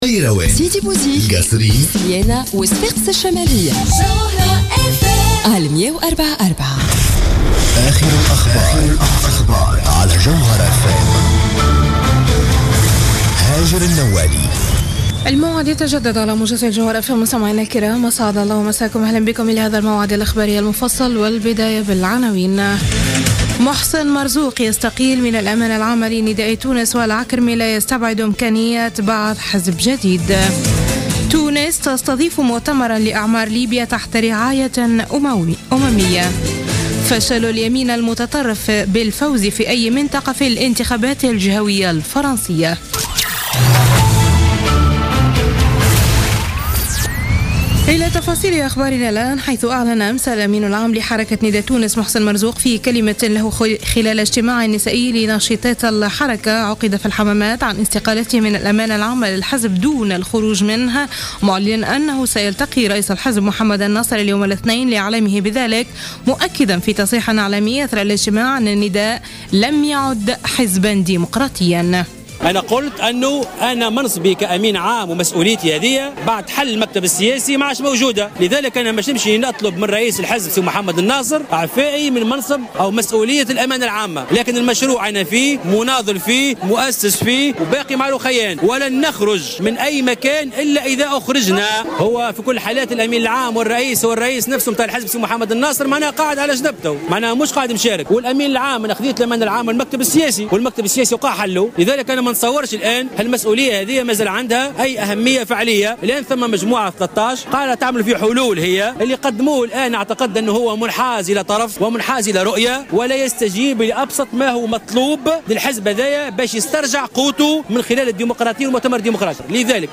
نشرة أخبار منتصف الليل ليوم الإثنين 13 ديسمبر 2015